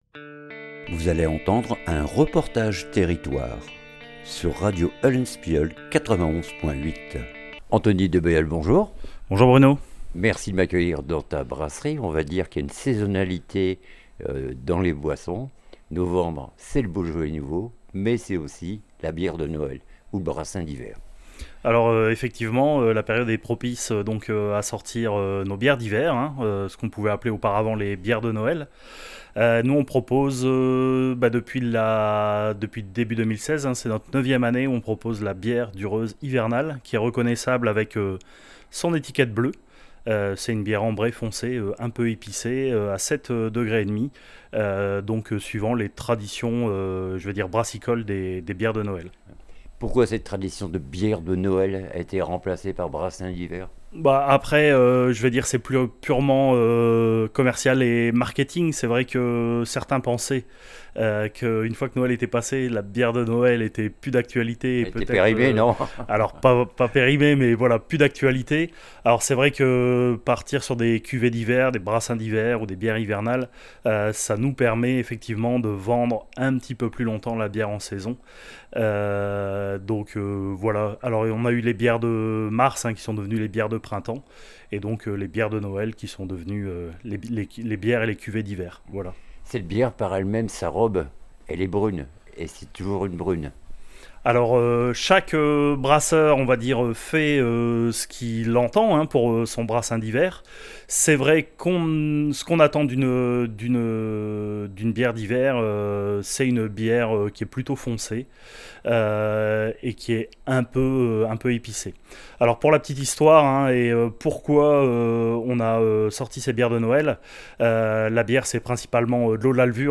REPORTAGE TERRITOIRE BIERE DU REUZE L HIVERNALE !